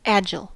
agile-us.mp3